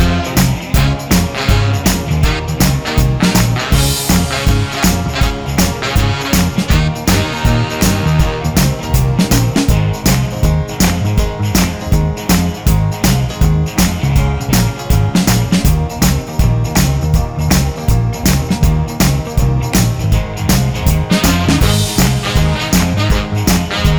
No Backing Vocals Christmas 3:52 Buy £1.50